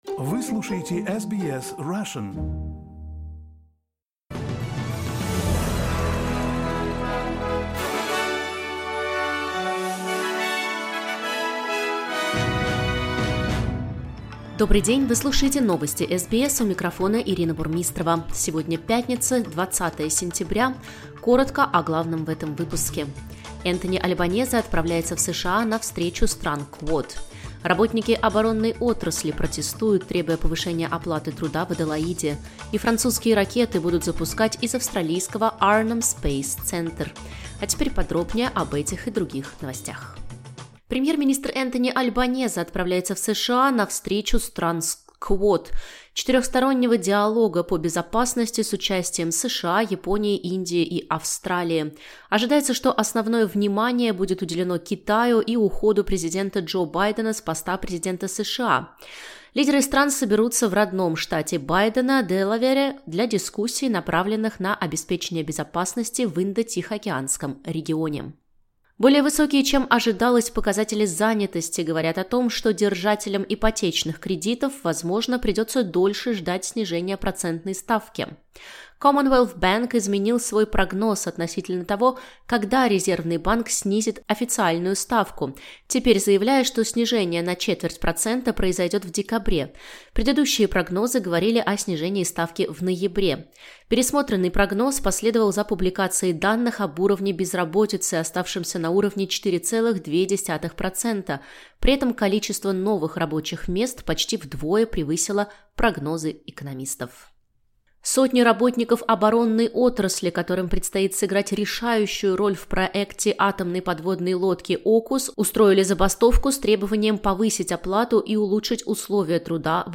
Новости SBS на русском языке - 20.09.2024